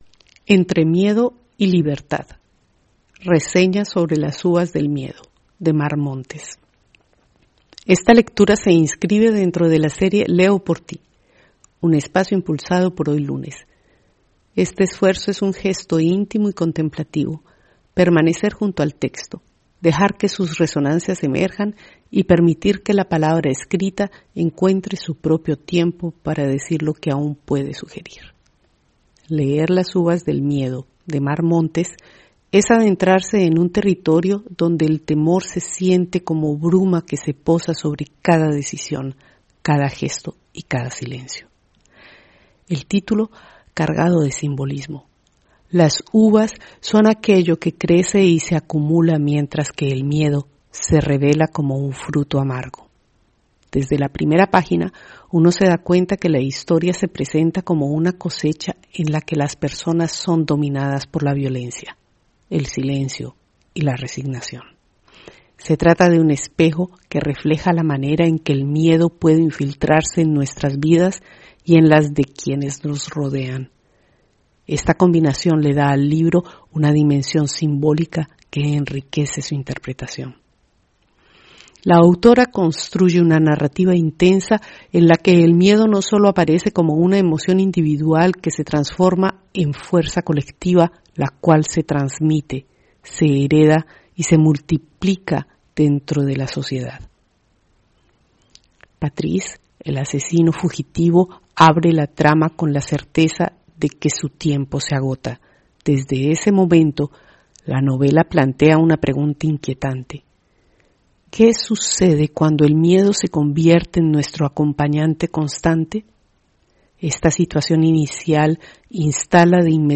HoyLunes – Esta lectura se inscribe dentro de la serie «Leo por ti», un espacio impulsado por HoyLunes.